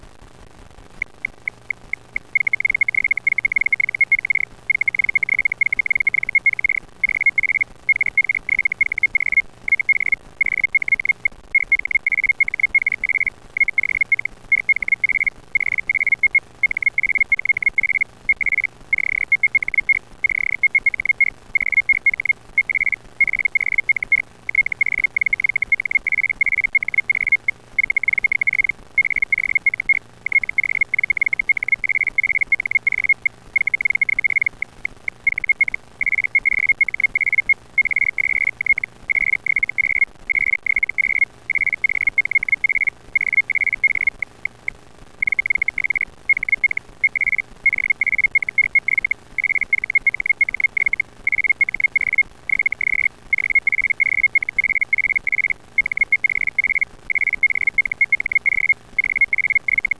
Ecco di seguito come suonano i vari modi:
feldhell
feldhellA.wav